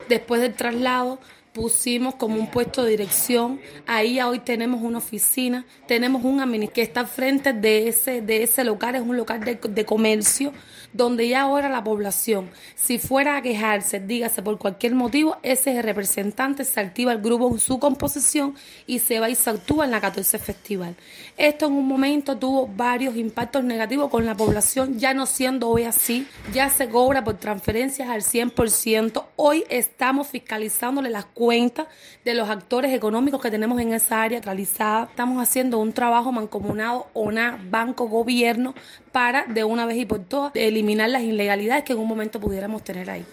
Yannia de la Caridad Ríos González, viceintendente del municipio de Matanzas, compartió con nuestra emisora algunas precisiones sobre los resultados de la implementación del traslado de los quioscos de las mipymes de la antigua plaza del mercado hacia la Plaza XIV Festival.